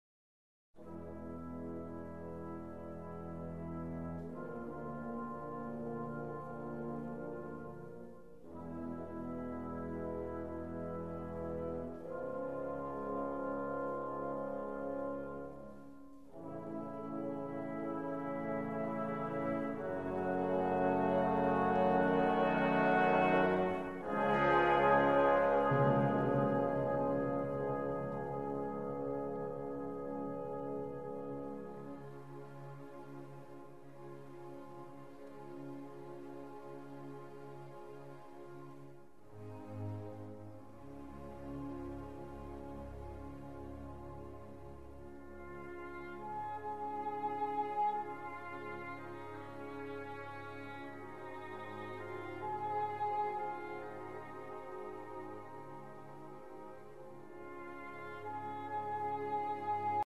Orchestral Works